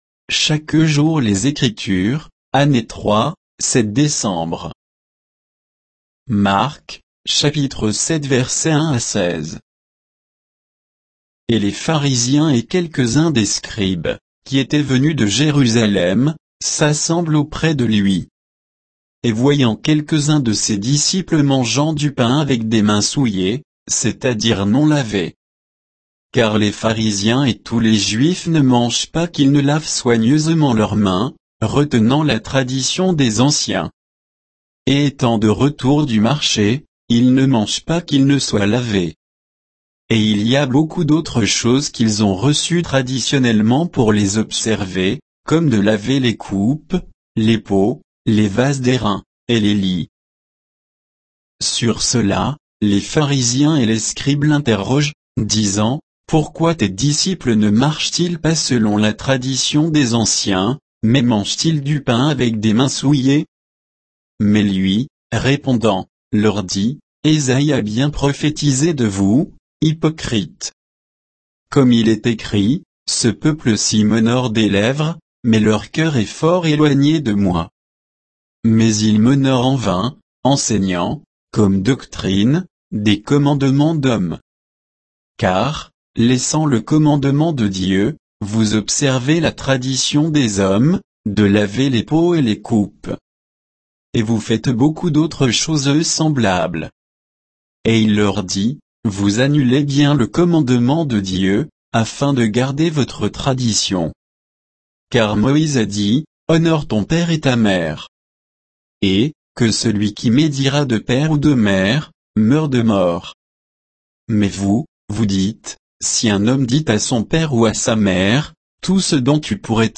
Méditation quoditienne de Chaque jour les Écritures sur Marc 7, 1 à 16